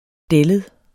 Udtale [ ˈdεləð ]